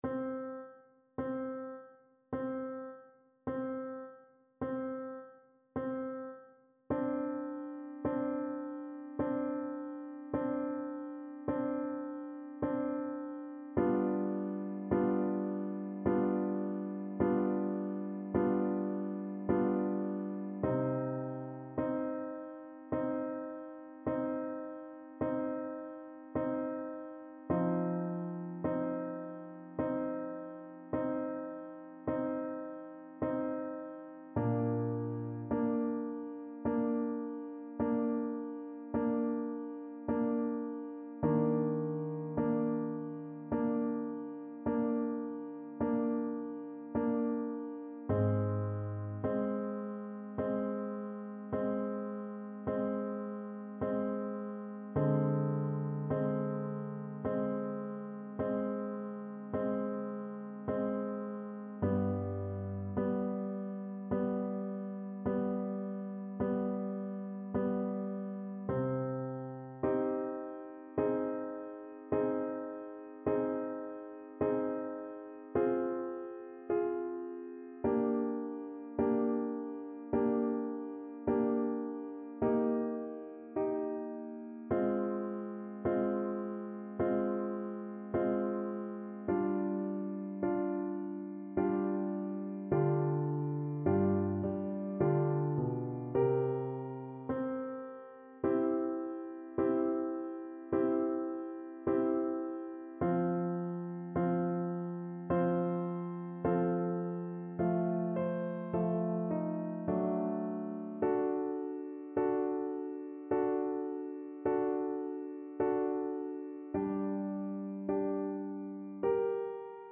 Play (or use space bar on your keyboard) Pause Music Playalong - Piano Accompaniment Playalong Band Accompaniment not yet available transpose reset tempo print settings full screen
Adagio =38 Adagio
3/4 (View more 3/4 Music)
Classical (View more Classical Clarinet Music)